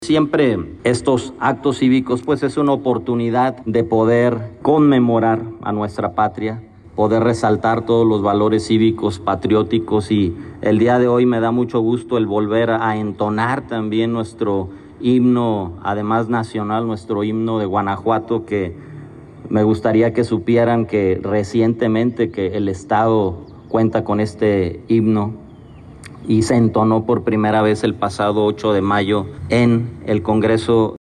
AudioBoletines
Rodolfo Gómez Cervantes, presidente municipal interino